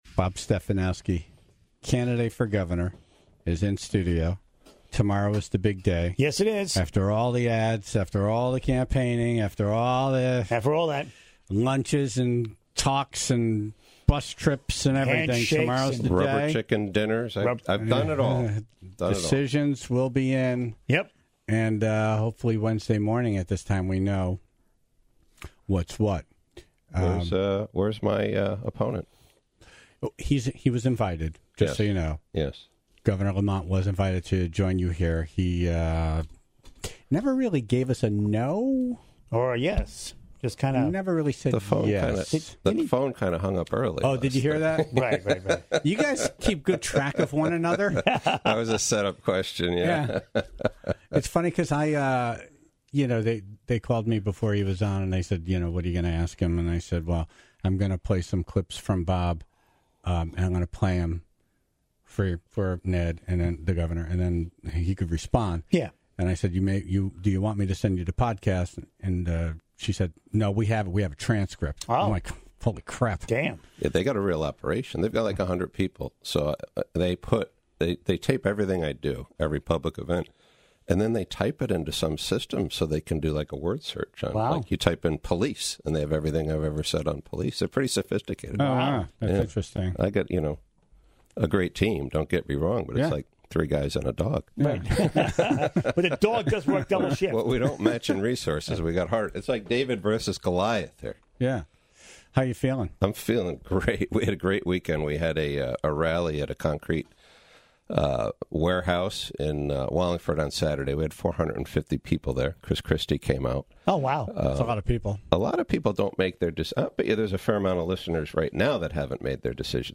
to take some last minute calls and questions from the Tribe. He covered everything from tolls, to state income tax, unions and more. His Lt. Governor Laura Devlin also made an appearance on the phone.